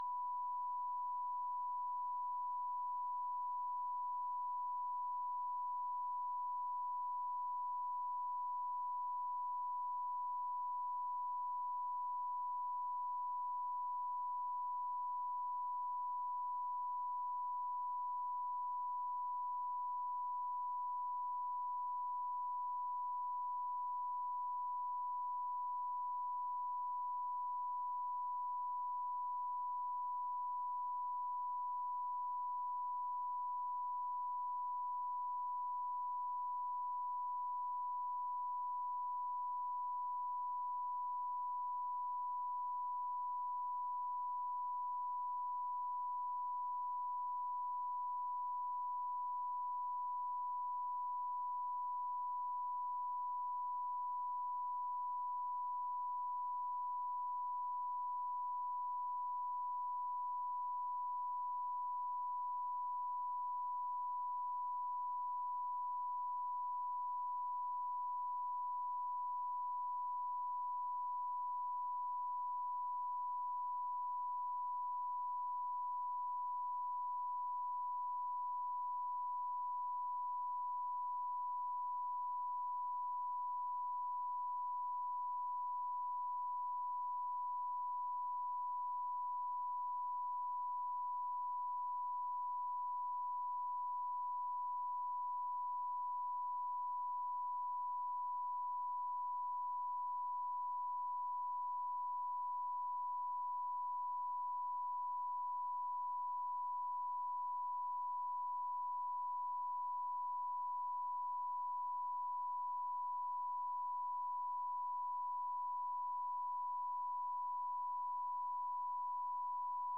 Conversation: 462-017
United States Secret Service agents
Recording Device: Oval Office
The Oval Office taping system captured this recording, which is known as Conversation 462-017 of the White House Tapes.
[Unintelligible]